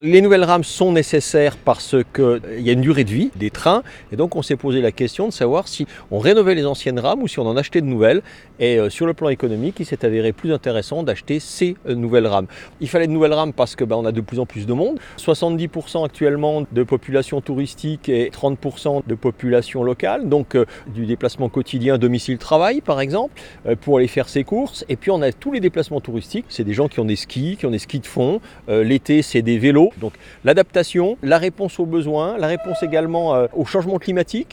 Éric Fournier, conseiller régional explique pourquoi il était nécessaire d’investir dans ce nouveau matériel roulant.